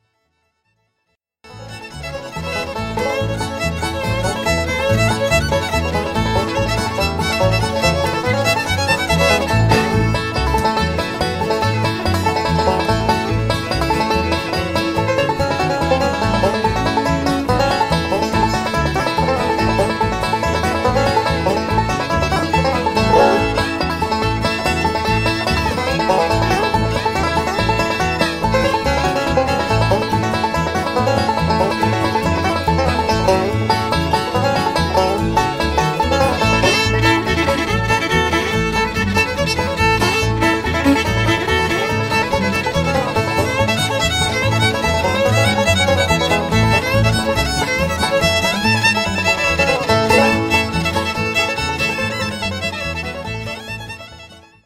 5-string banjo